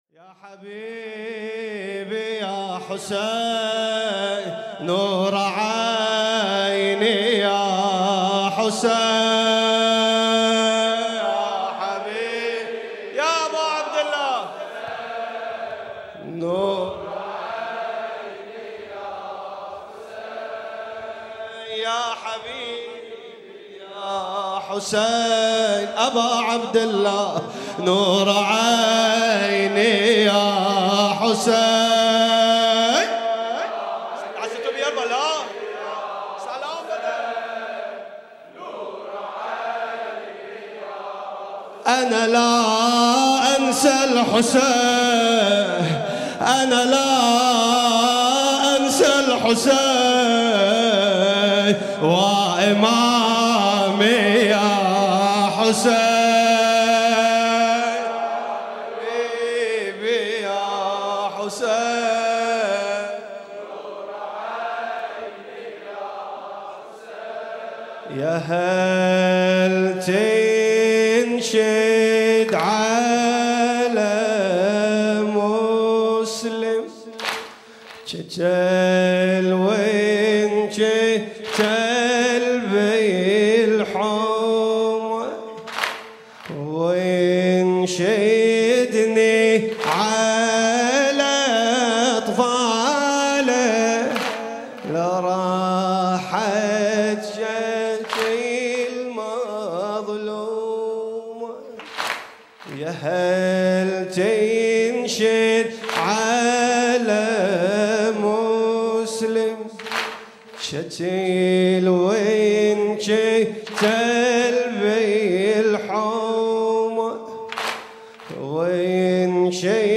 گلچين محرم 95 - واحد - شوط کربلایی یا هالتن